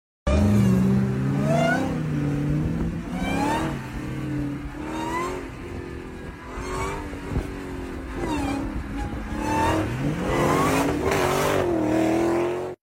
Dodge Demon Supercharger Hit Different sound effects free download